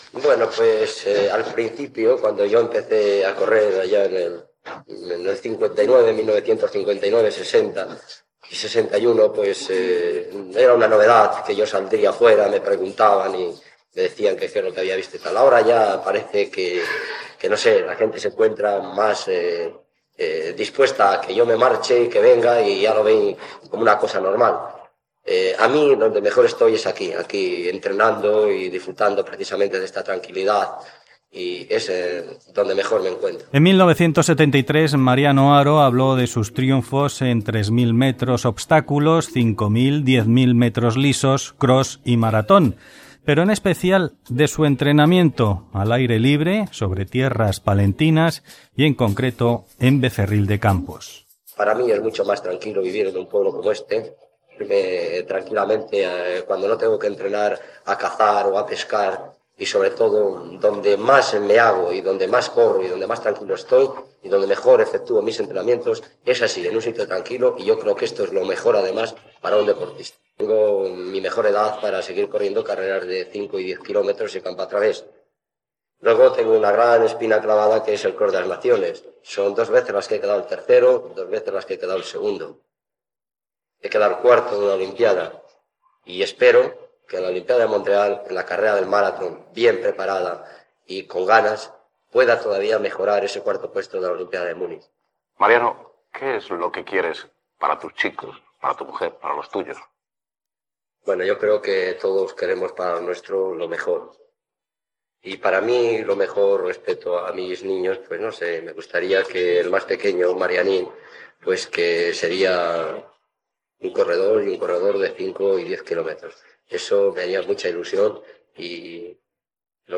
L'atleta de fons Mariano Haro explica com s'entrenava i parla de la seva família